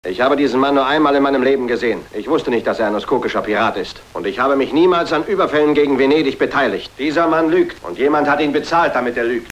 Hörprobe des deutschen Synchronschauspielers (149 Kb)